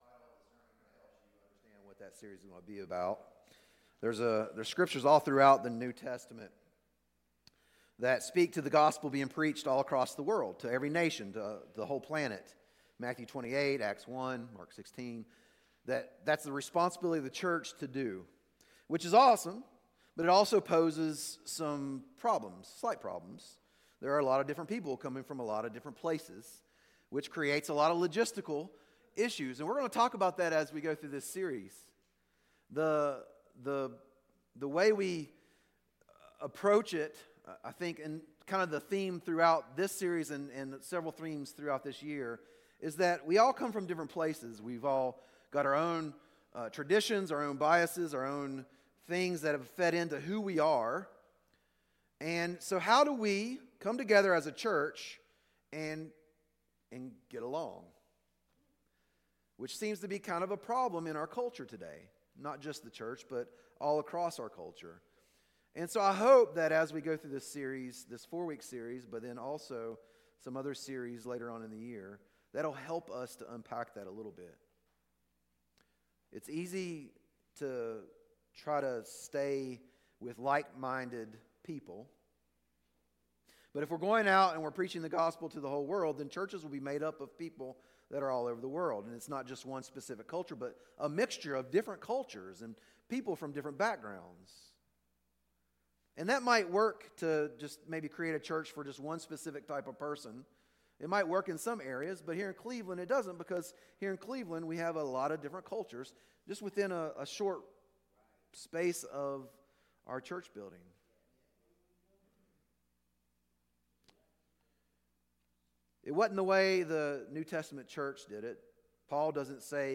Sermons | Scranton Road Bible Church